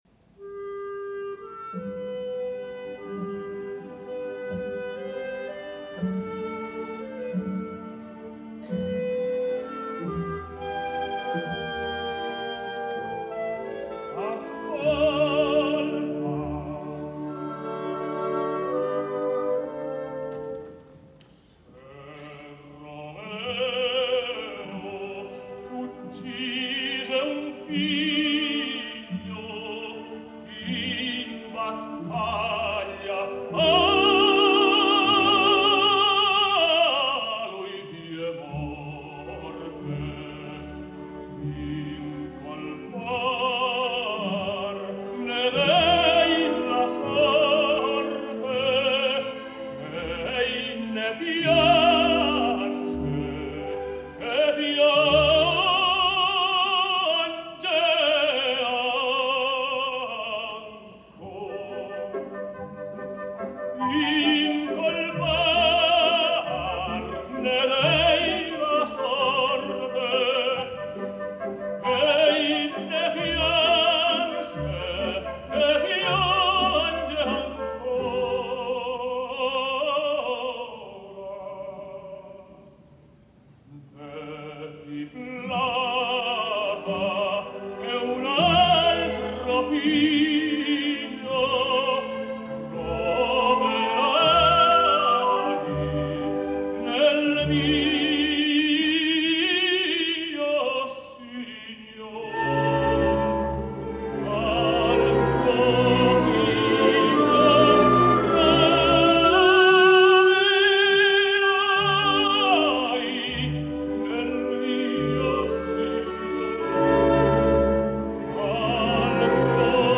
На всякий случай, может быть , будет интересно послушать и первую часть арии Ромео из 1 действия ( она тоже очень красива) в исполнении Д.Арагалла.